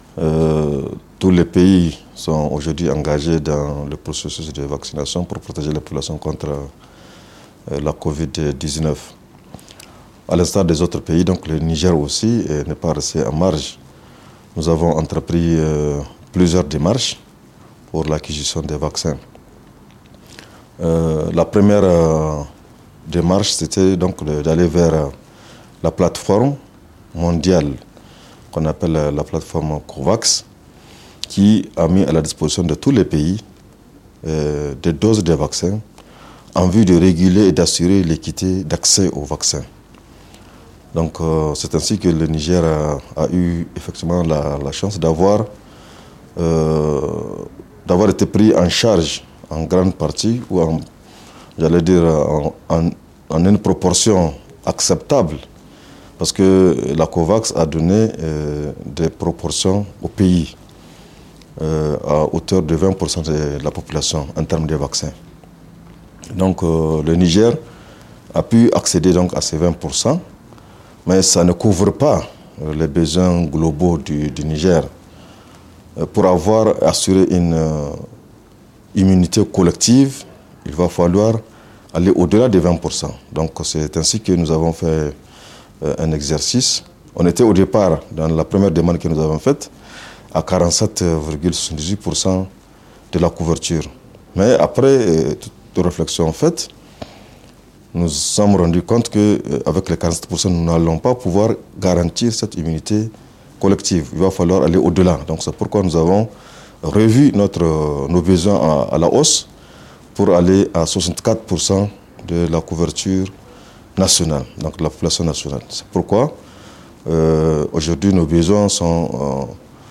Interview de Dr Ranaou Abaché, le secrétaire générale du ministère de la santé